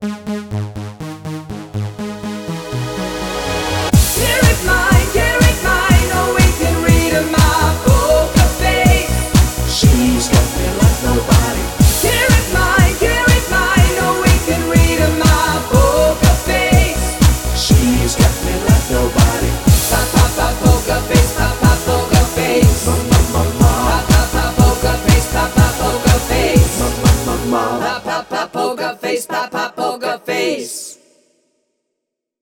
Coverband